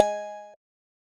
Button.ogg